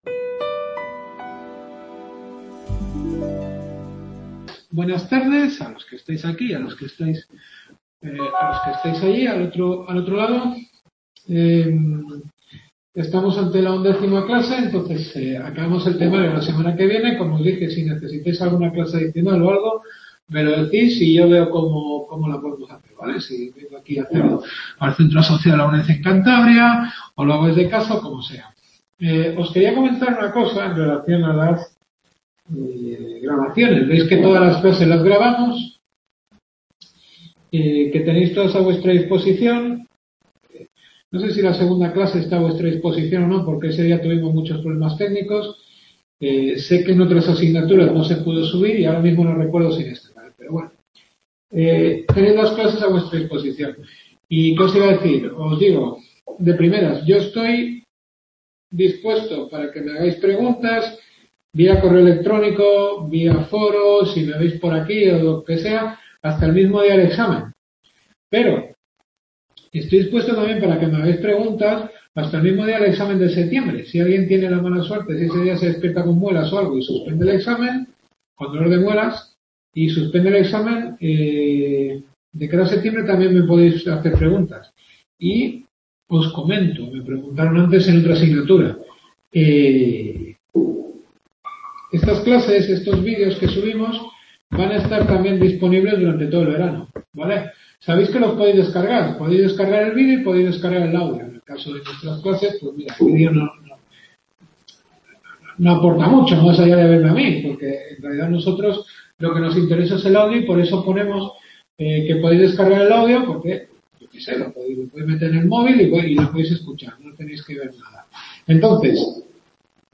Cultura Europea en España. Undécima clase.